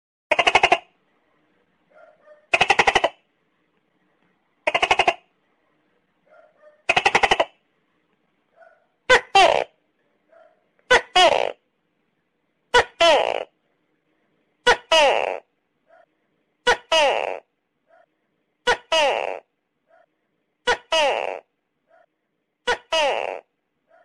Gecko Bouton sonore
Animal Sounds Soundboard1,764 views